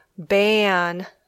e sound æ sound